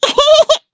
Taily-Vox_Happy2.wav